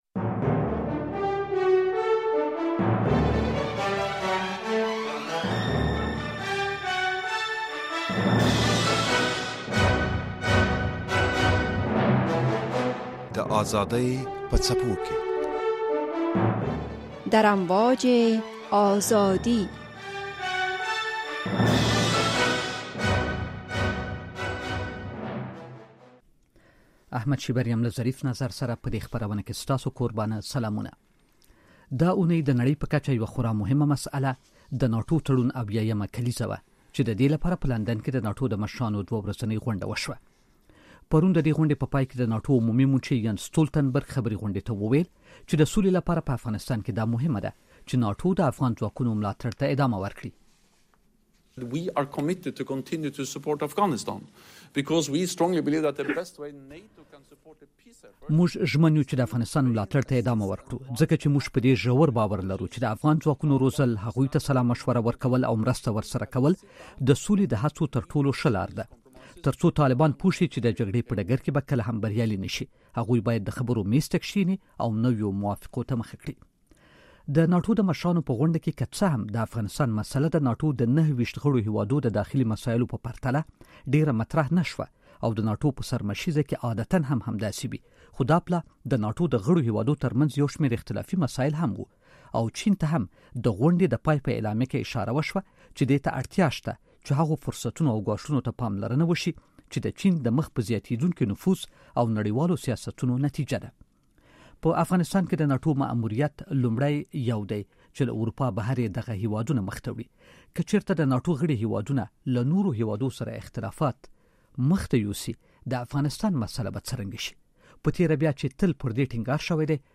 در نشست سران ناتو که در این هفته در لندن برگزار شد، مسئله افغانستان چگونه مطرح شد؟ در این مورد با مهمانان برنامه بحث داریم.